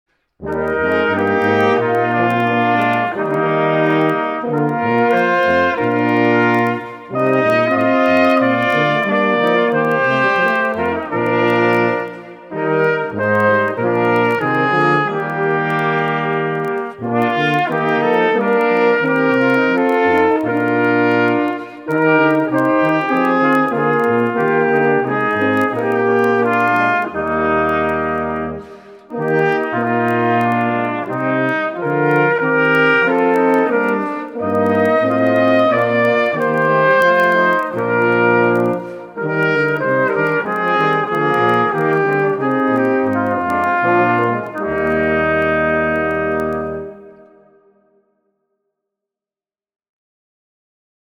Barocke Kirchenmusik für Blechblasinstrumente
2x Trompete, Es-Horn, Es-Tuba [0:51]